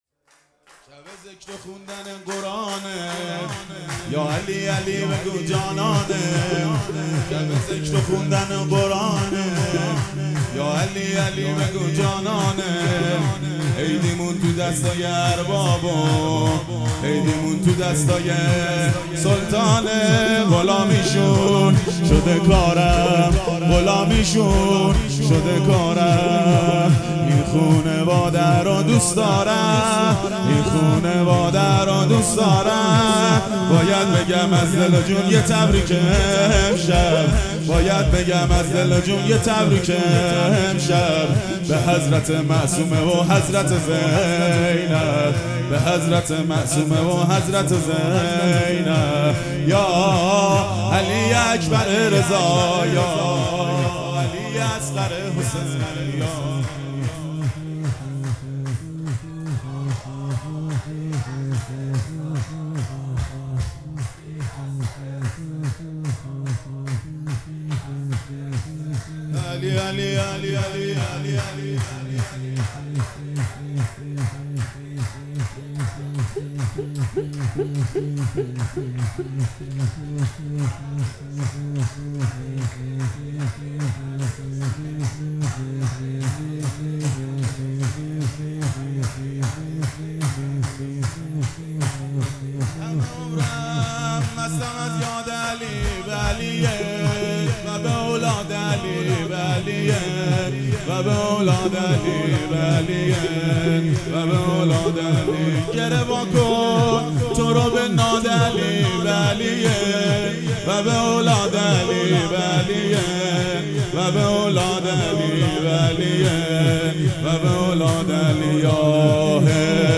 صوت مراسم ولادت امام جواد(ع)